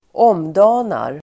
Ladda ner uttalet
Uttal: [²'åm:da:nar]